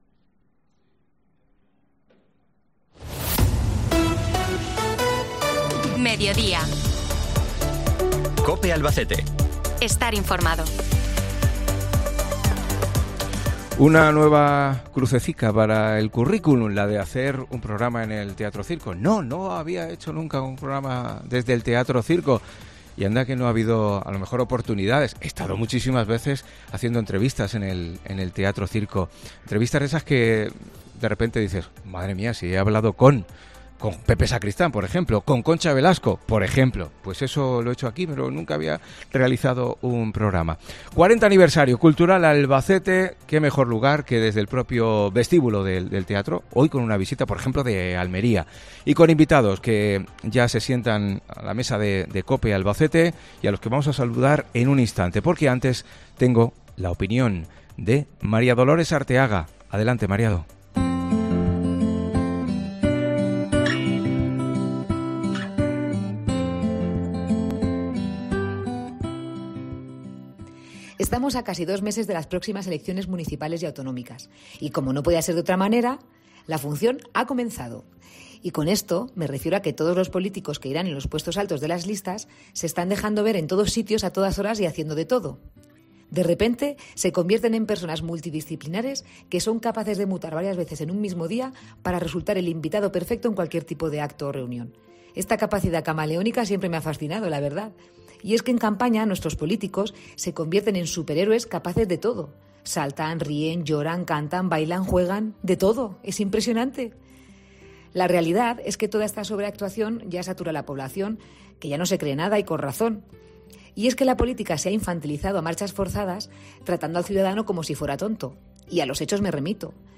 Desde el Teatro Circo de Albacete